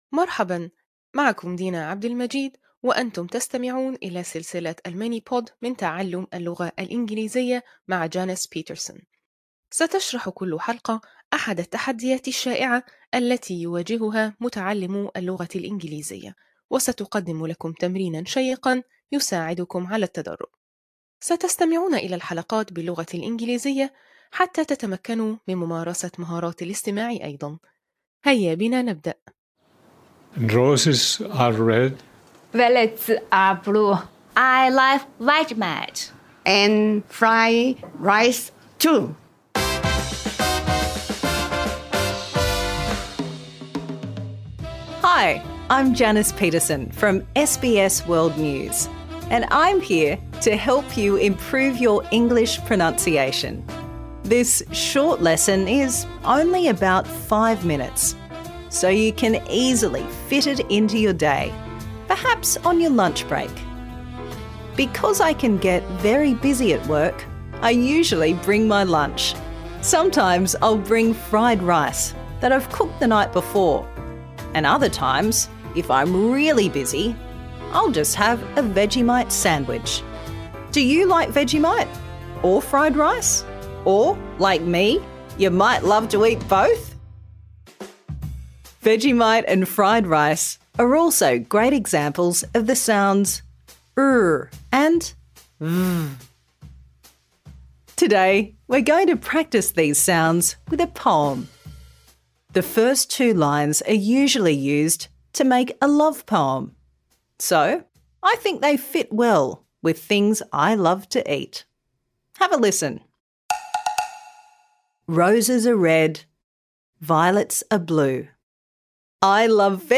في هذا الدرس الذي يستغرق خمس دقائق، ستتمكن من تحسين نطقك من خلال الاستماع والمتابعة